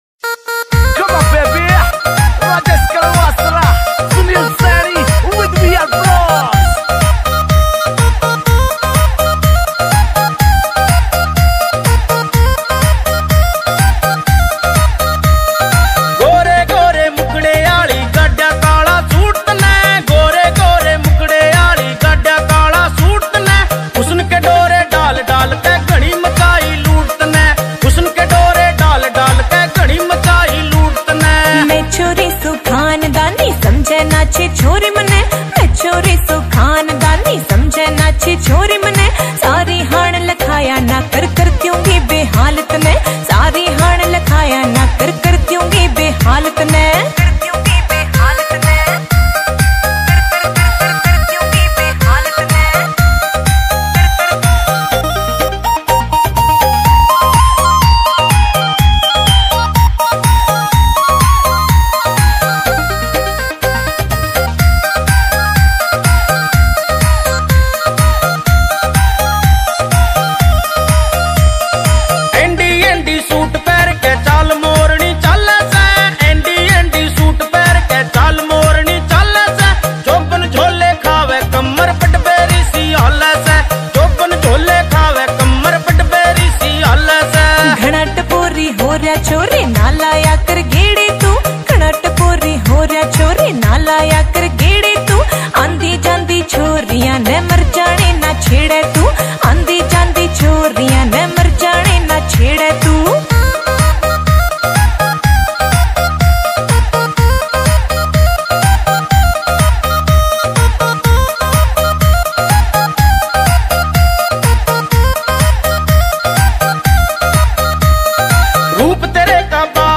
» Haryanvi Songs